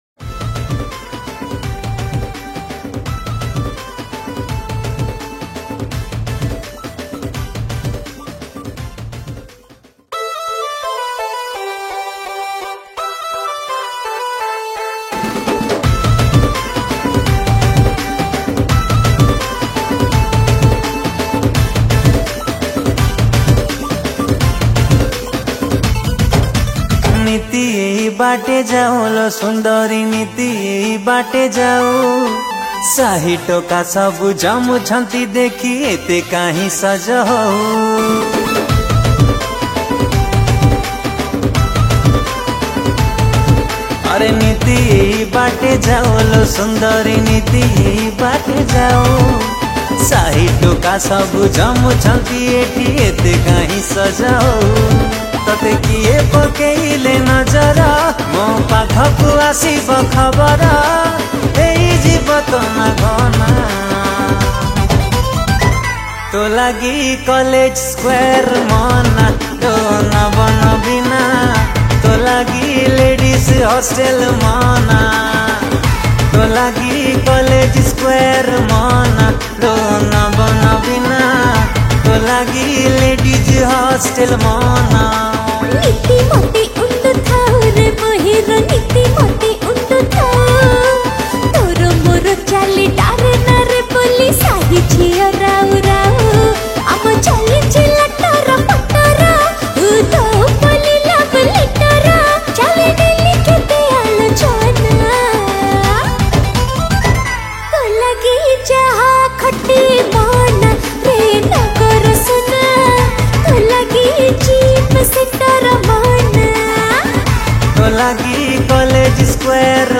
Keyboard Programming
Rhythm Programming
Single Odia Album Song 2024